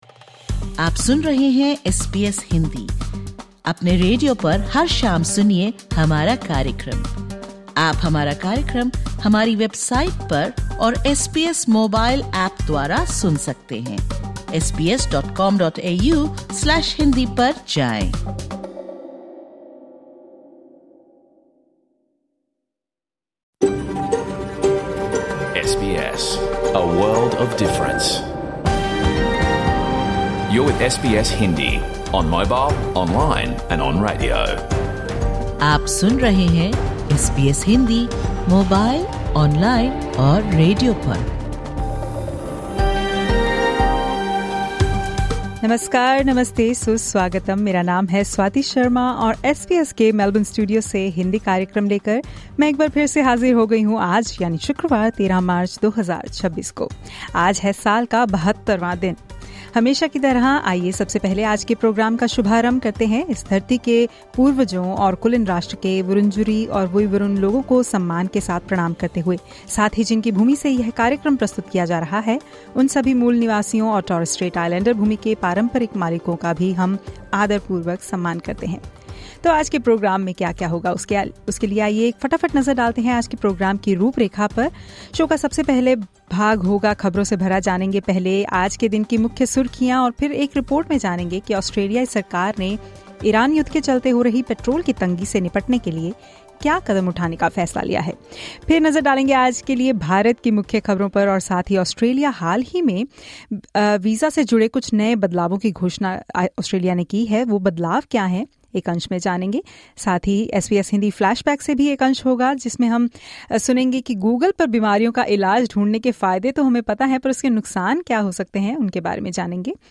Catch the full radio program of SBS Hindi